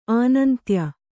♪ ānantya